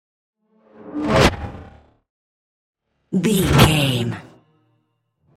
Sci fi vehicle whoosh fast
Sound Effects
futuristic
whoosh